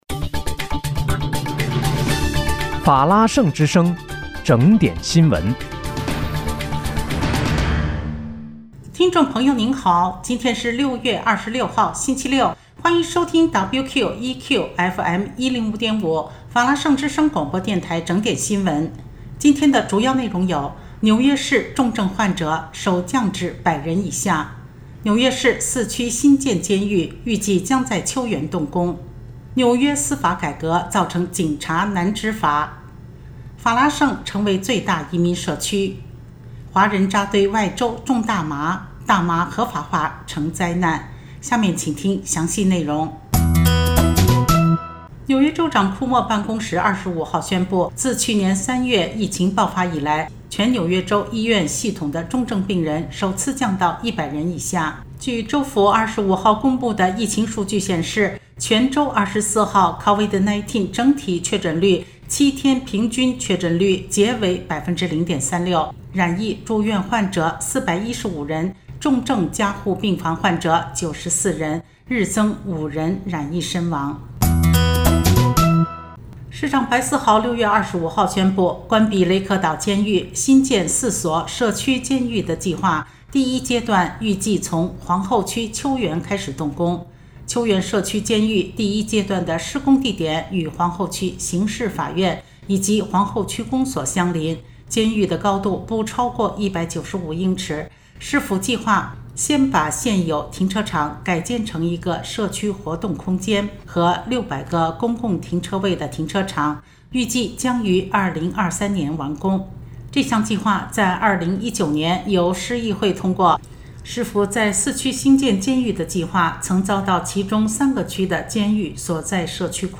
6月26日（星期六)纽约整点新闻